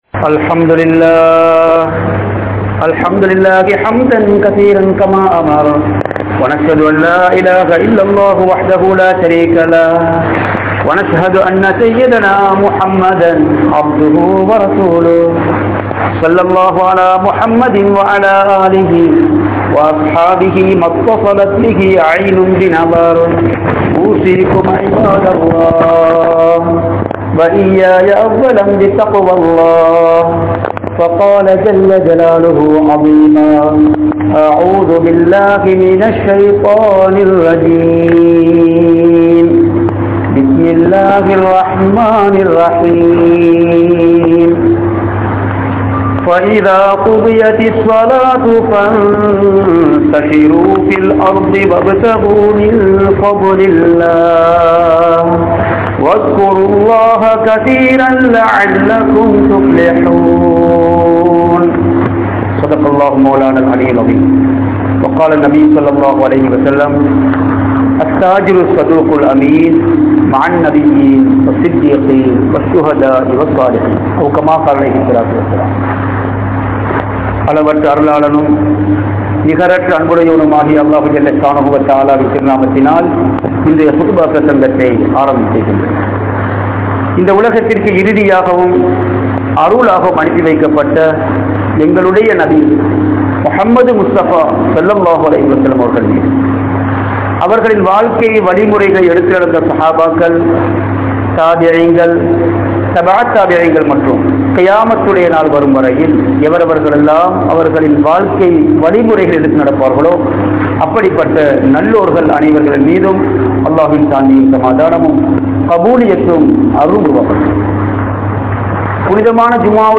Islam Koorum Viyaafaari Yaar? (இஸ்லாம் கூறும் வியாபாரி யார்?) | Audio Bayans | All Ceylon Muslim Youth Community | Addalaichenai